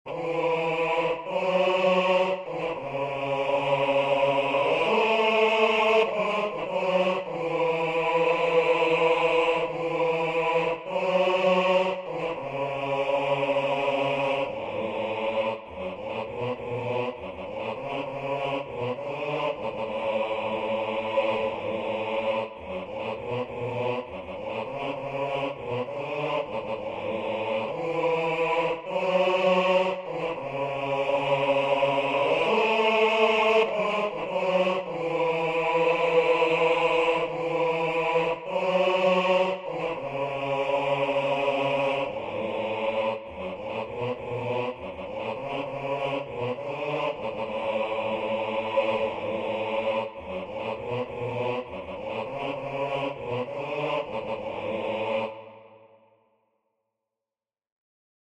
1 The song sung is the Koromanti Death Song, always used by the Maroons at a burial.